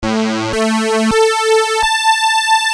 demo Hear OKI sawtooth wave
saw.mp3